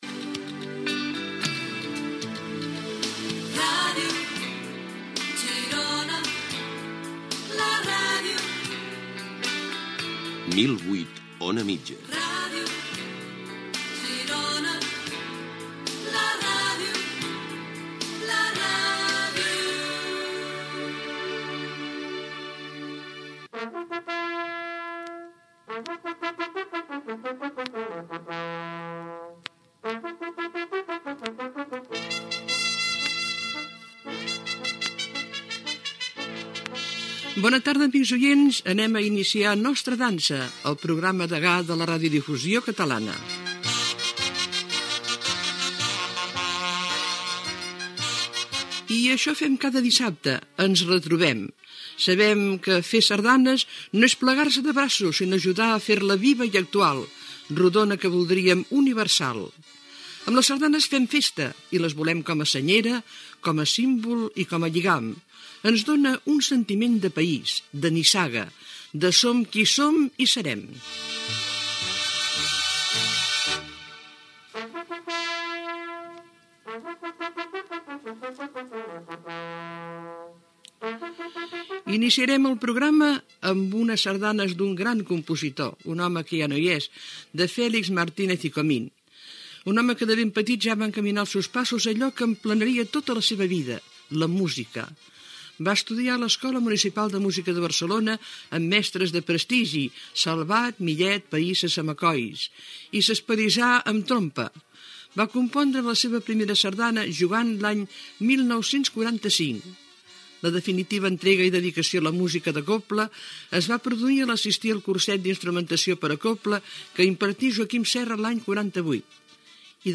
Indicatiu de l'emissora, comentari sobre la sardana, el compositor Fèlix Martínez i Comín, tema musical
Musical